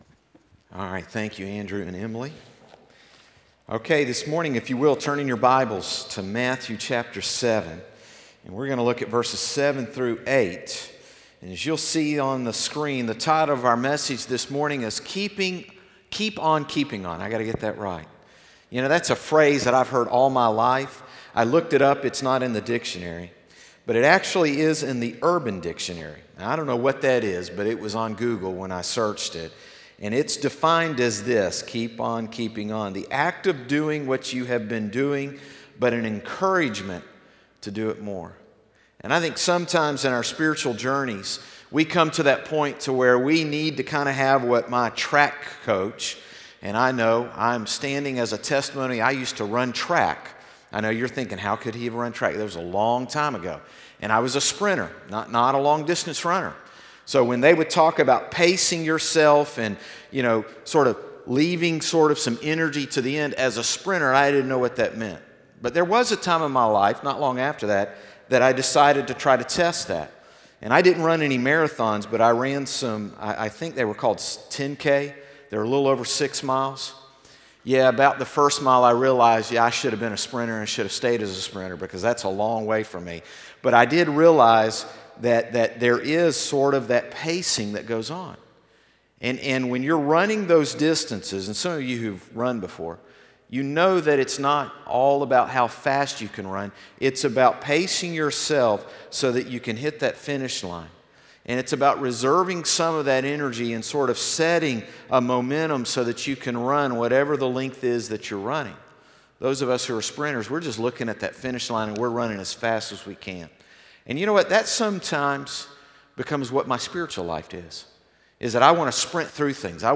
Sermons - Concord Baptist Church
Morning Service 7-29-18.mp3